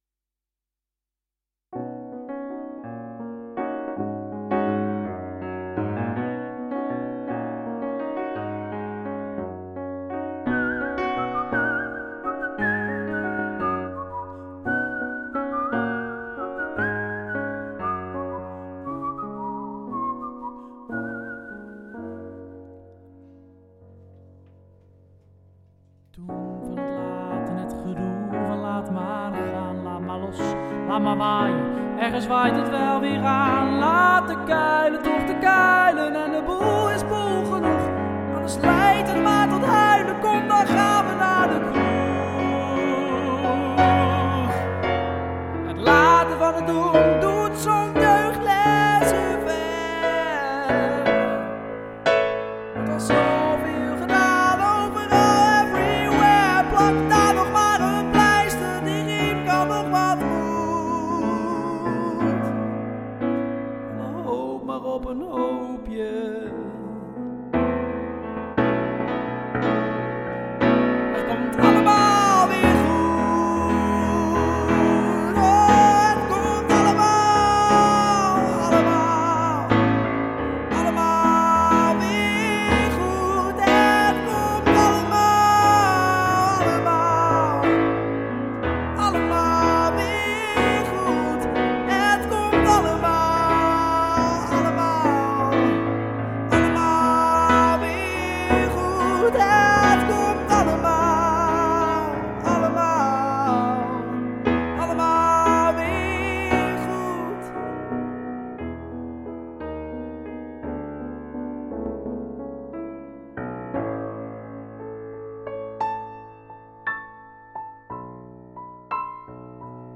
Hoewel een fantastische vertolking, met bovendien een geweldig aanstekelijk en melancholisch fluitje erin, werd ‘Puik’ enorm gemist.